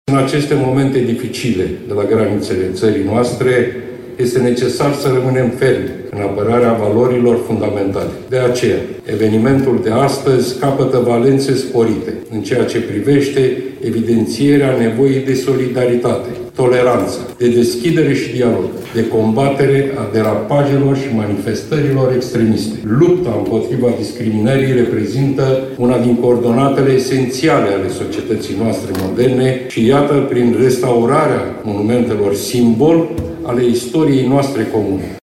eclarațiile lui Marcel Ciolacu vin în contextul redeschiderii sinagogii din Cetate, eveniment la care a fost invitat să participe.
02-Marcel-Ciolacu-sinagoga.mp3